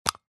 Звуки аэрозоля
Звук зняття кришки з дезодоранта